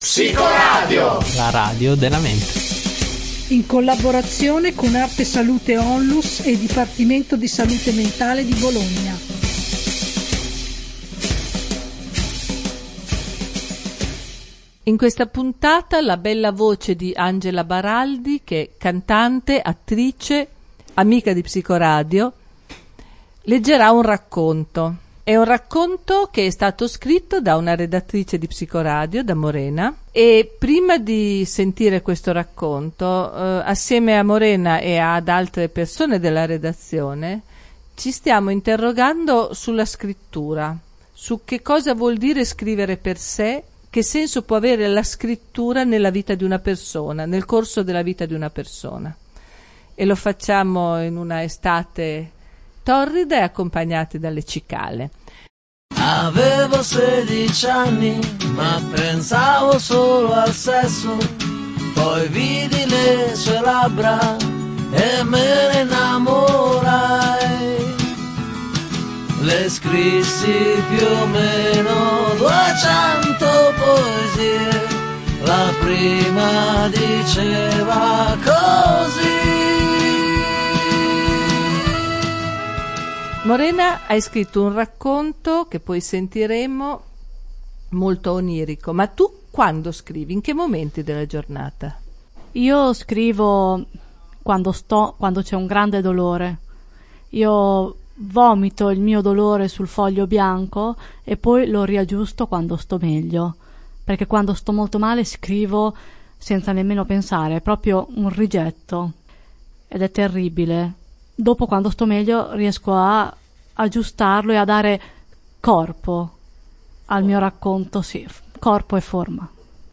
In questa puntata l’attrice, cantante Angela Baraldi dà voce al racconto di una psicoredattrice. Da qui l’idea di chiedere agli altri redattori della mente il loro rapporto con la scrittura.